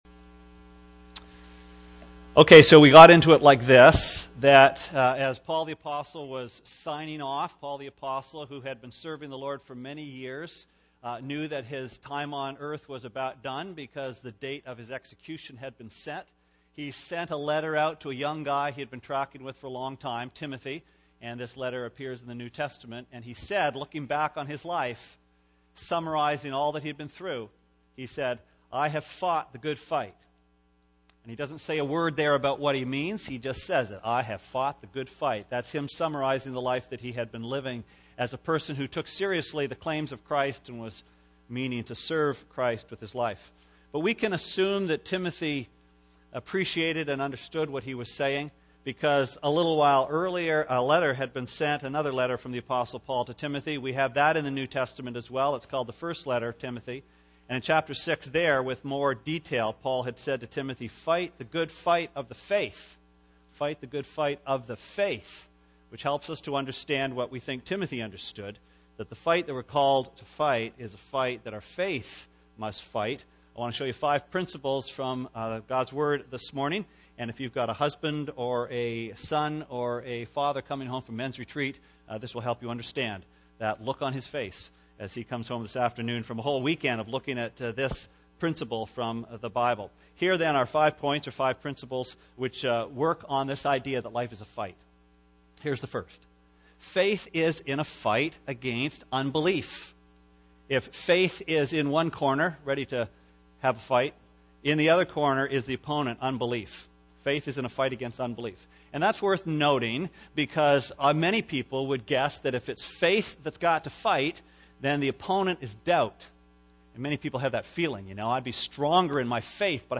Sermon Archives Oct 14